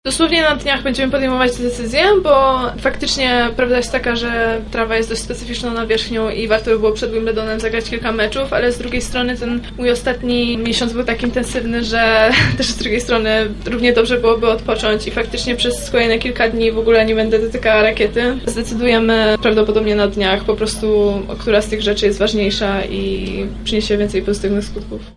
Na konferencji prasowej nie zabrakło pytań w stronę polskiej tenisistki o jej niedawny sukces w turnieju Rollanda Garrosa.
– tłumaczy Iga Świątek, polska tenisistka.